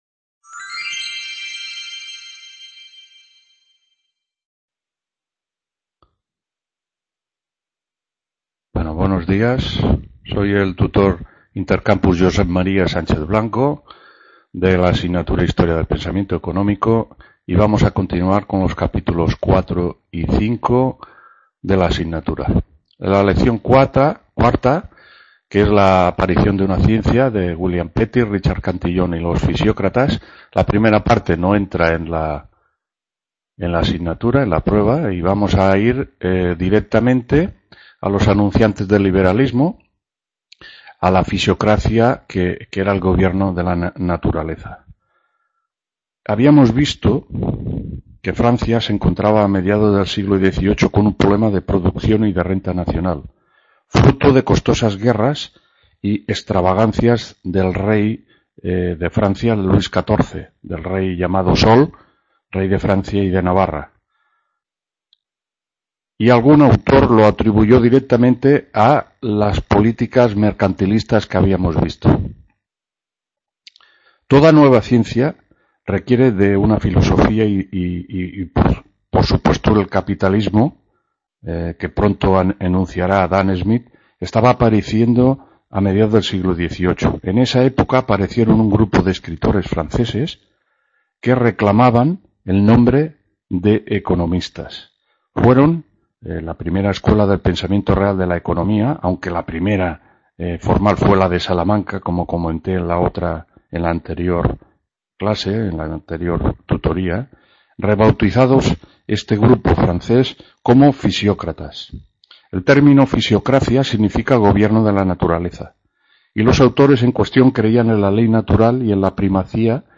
2ª TUTORÍA HISTORIA DEL PENSAMIENTO ECONÓMICO 22-10-15…